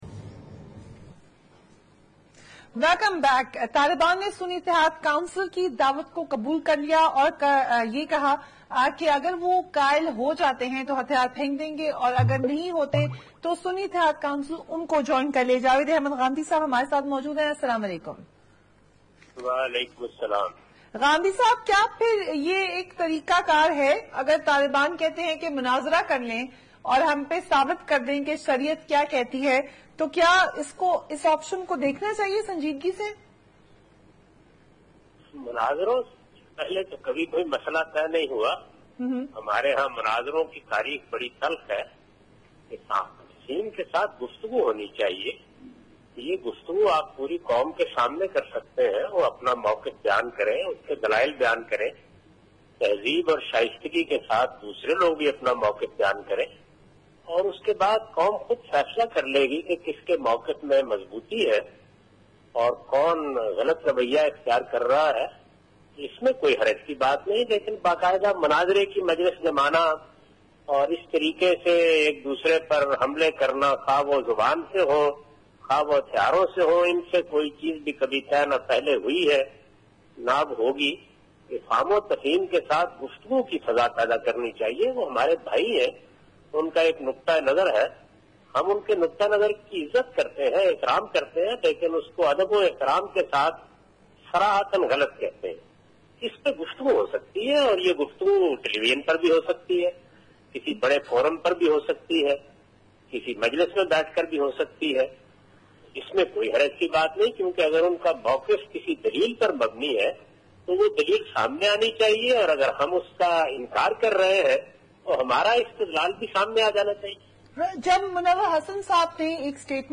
Category: TV Programs / Questions_Answers /
Javed Ahmad ghamidi discussed about “Who is a Martyr, who is not? Why is this discussion?” in 8 PM with Fereeha Idrees on Waqat News.